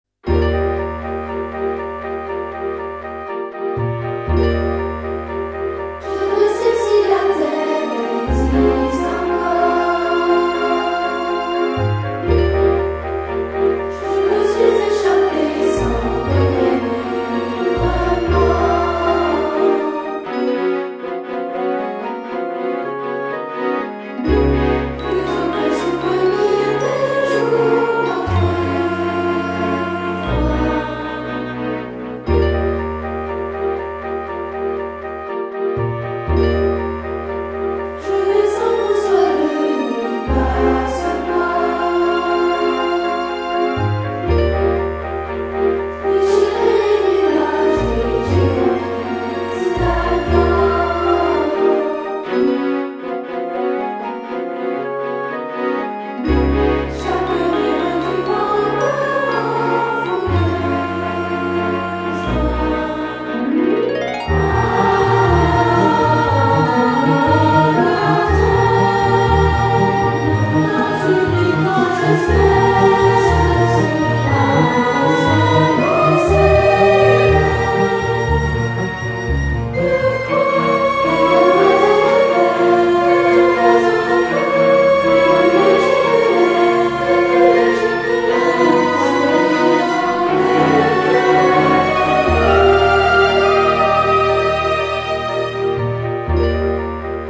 enregistré dernièrement à Toulouse
choeur